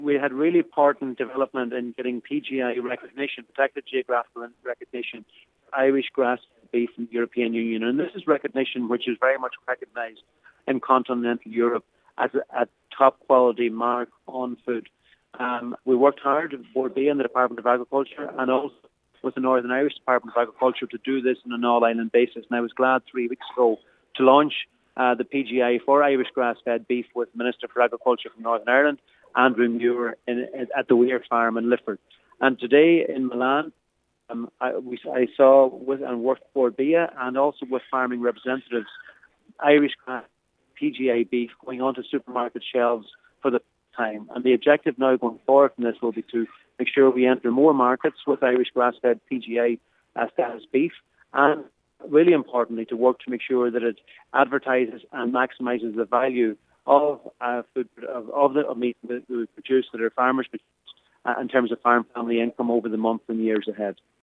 Agriculture Minister Charlie McConalogue is in Milan for the occasion.
Minister McConalogue says moving forward, the focus will shift to expanding into additional markets: